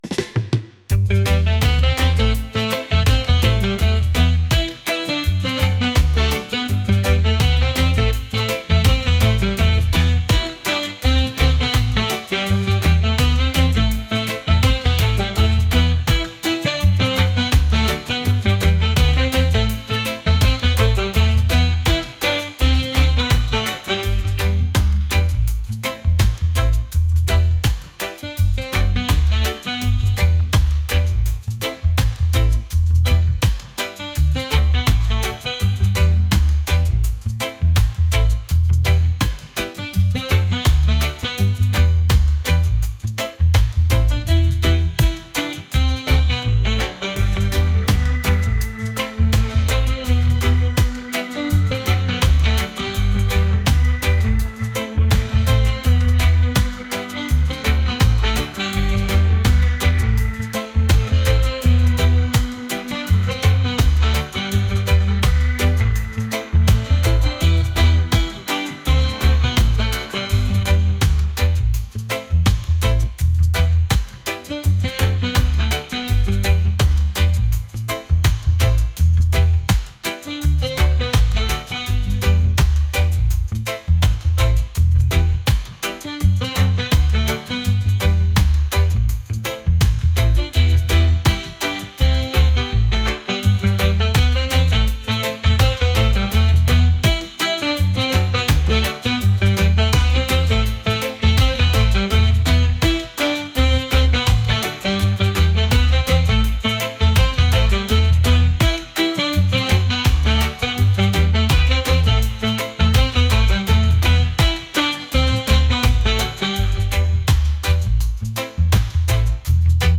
reggae | pop | folk